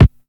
• HQ Subby Rap Kick Single Hit G# Key 668.wav
Royality free bass drum sound tuned to the G# note. Loudest frequency: 250Hz
hq-subby-rap-kick-single-hit-g-sharp-key-668-9md.wav